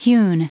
Prononciation audio / Fichier audio de HEWN en anglais
Prononciation du mot hewn en anglais (fichier audio)